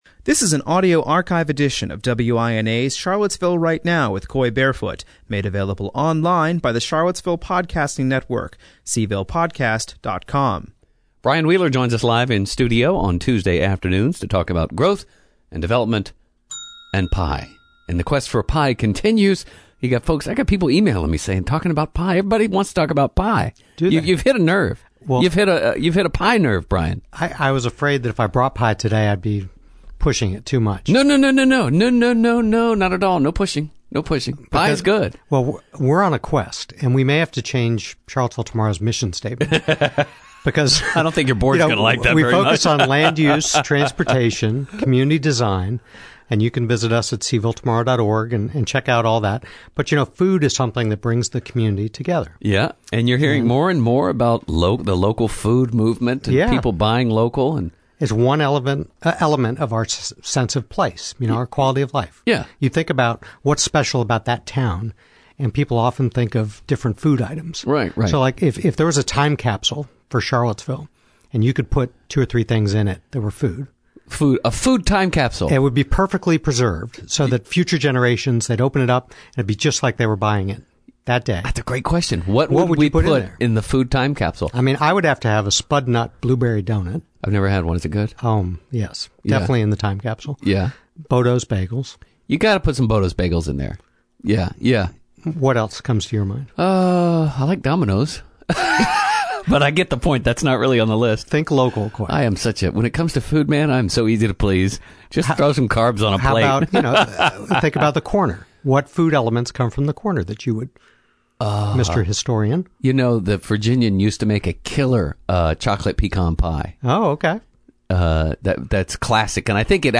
The Charlottesville City Council has held its first meeting of 2008 welcoming newly-elected members Satyendra Huja and Holly Edwards. City Manager Gary O’Connell called the meeting to order as the Council’s first order of business was its biennial organizational meeting in which it elects a Mayor and Vice Mayor to two-year terms.